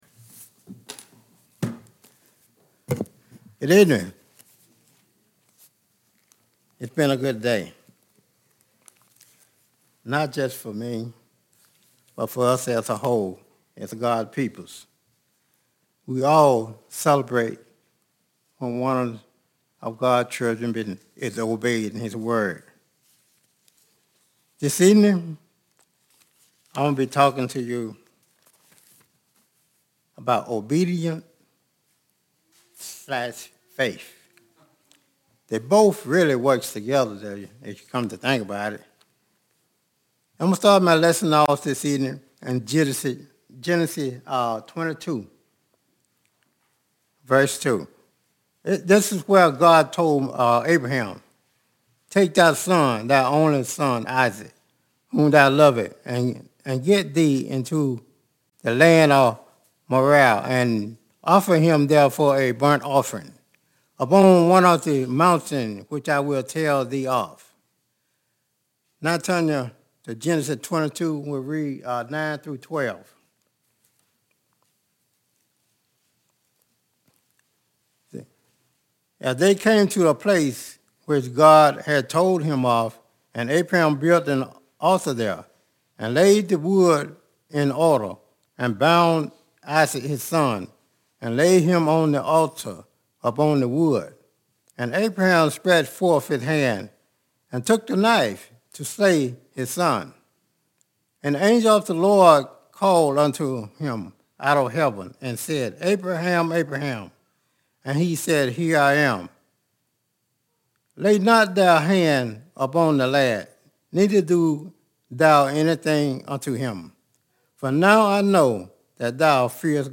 Sun PM Worship